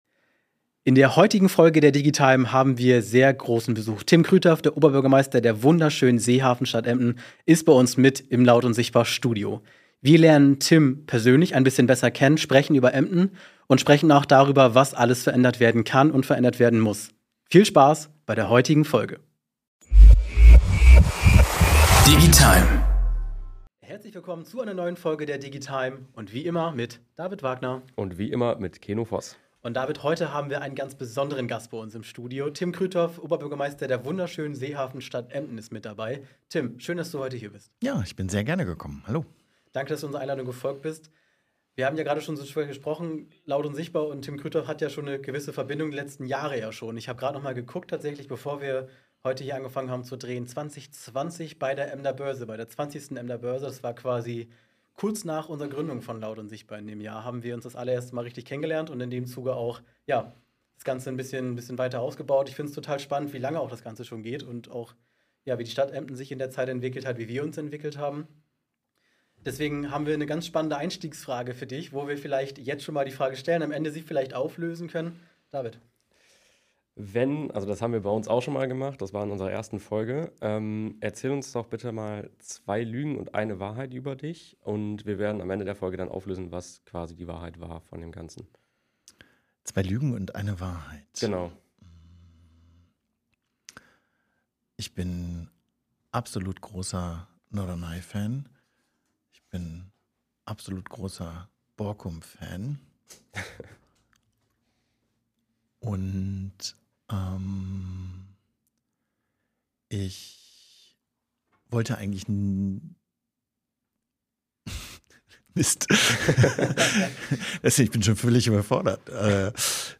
Großer Besuch im Laut & Sichtbar Studio!